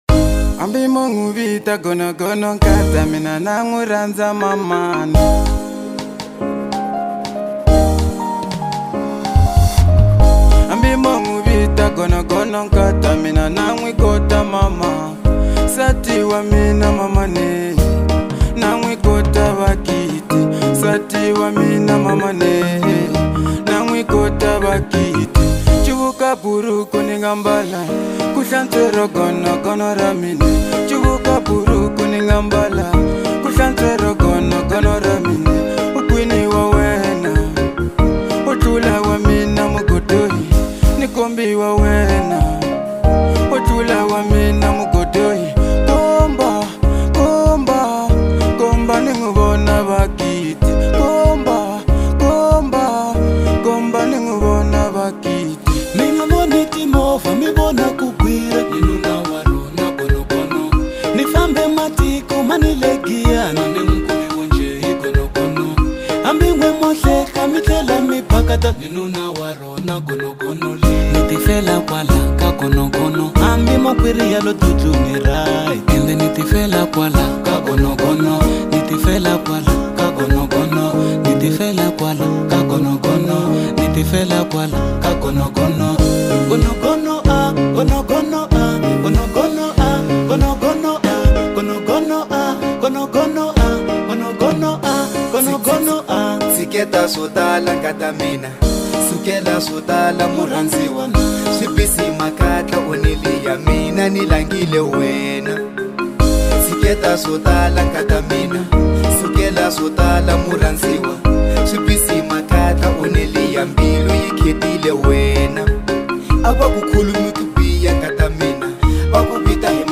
Marrabenta